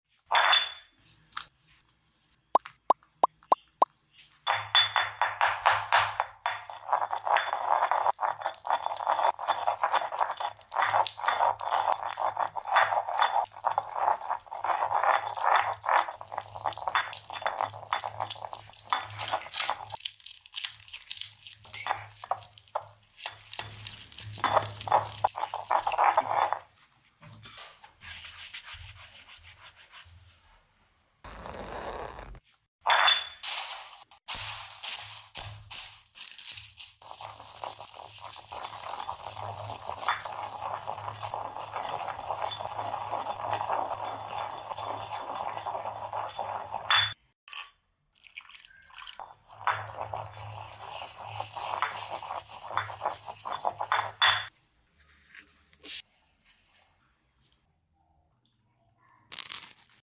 Upload By ASMR videos
Oddlysatisfying crushing lipsticks into Slime